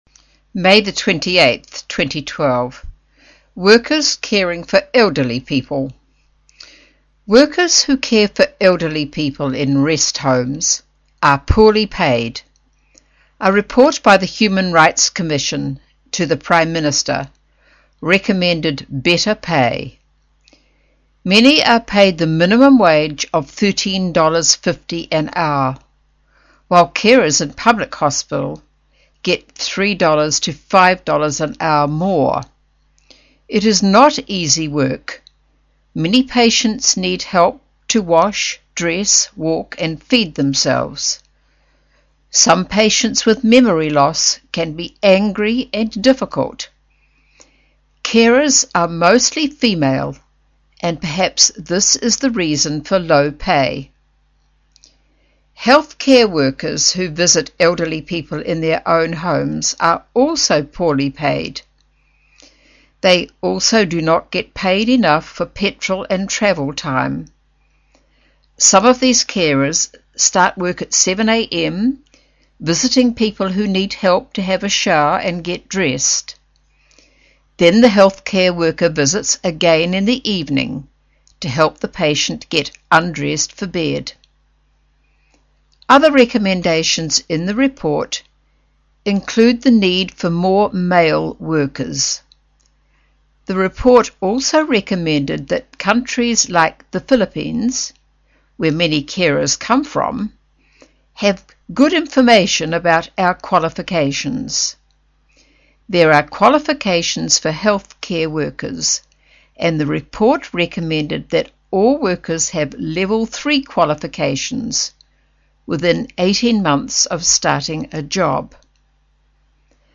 新西兰英语 605 Workers caring for elderly people 听力文件下载—在线英语听力室